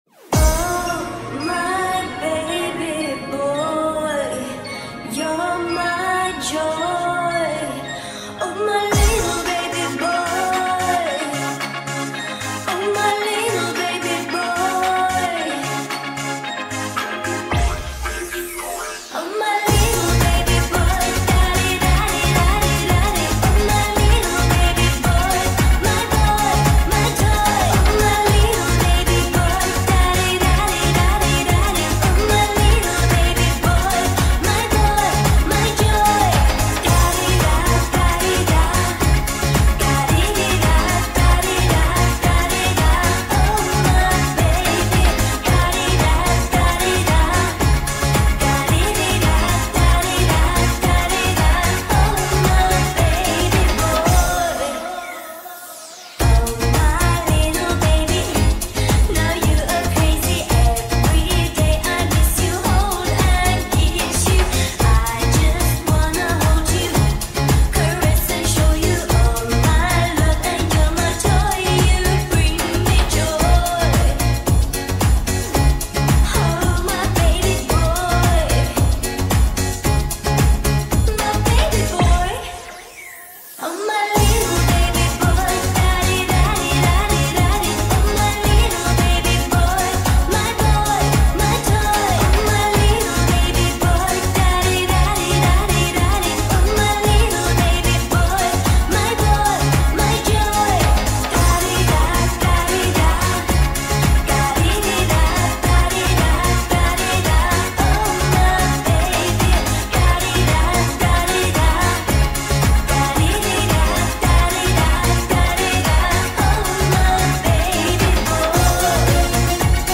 شاد
عاشقانه